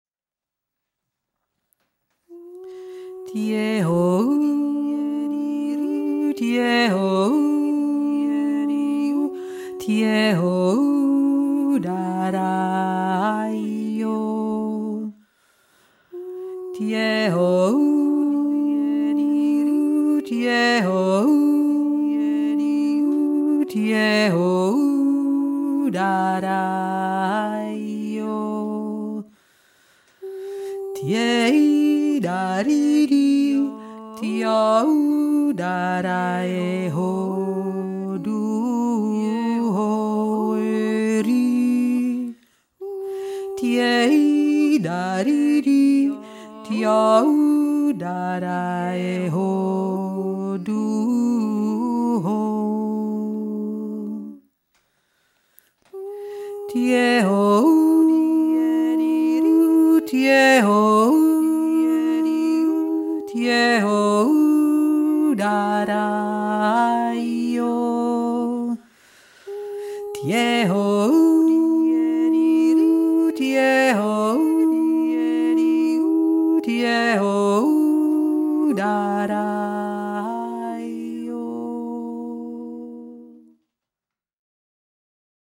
2. Stimme